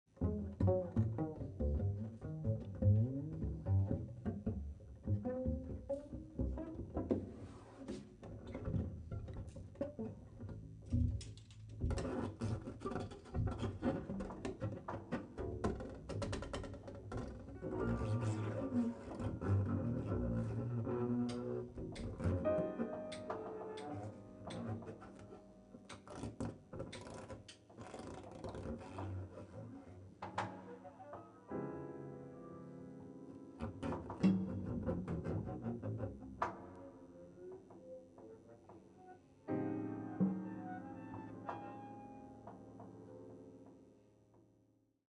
Recorded on at Jazz Spot Candy
いつものように何も決めずに互いに刺激し合い自由にその場で曲を創り上げる。